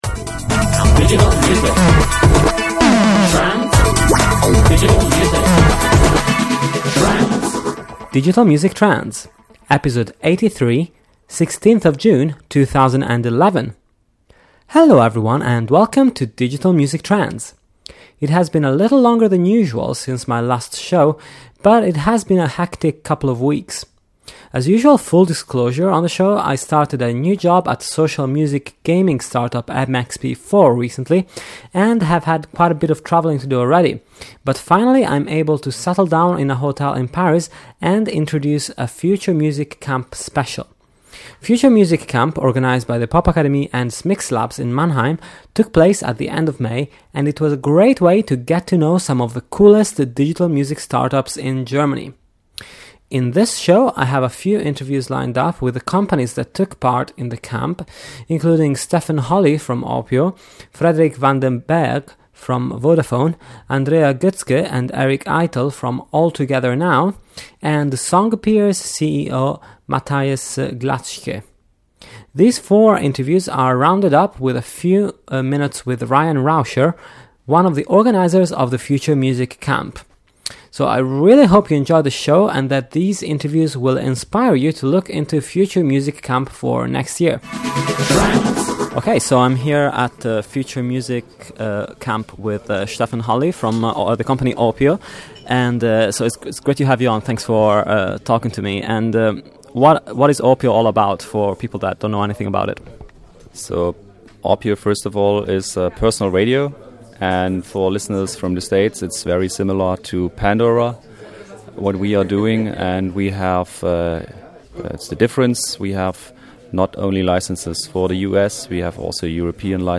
This week on the show a special from Future Music Camp - held at the Popakademie in Mannheim at the end of May.